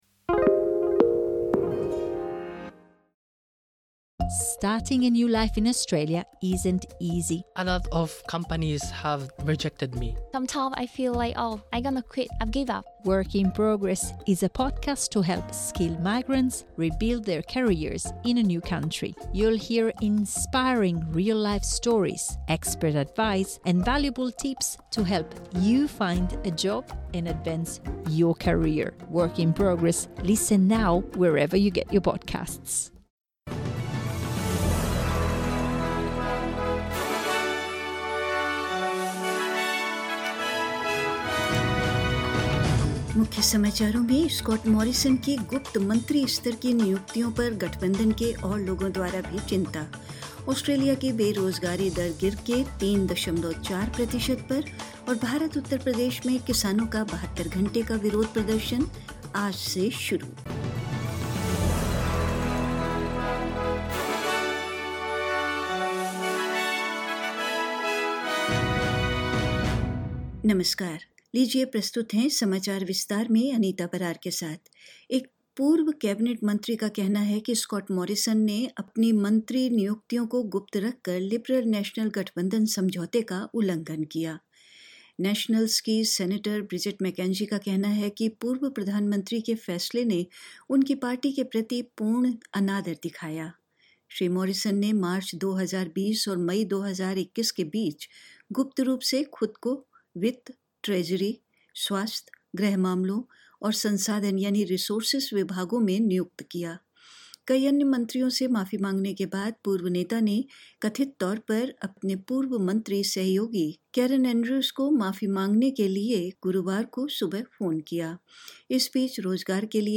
In this latest bulletin: Further Coalition voices express concern over Scott Morrison's secret ministerial appointments; Australia's unemployment rate has dropped to 3.4 per cent; In India, Farmers’ 72 hour Protest In Uttar Pradesh begins today and more news.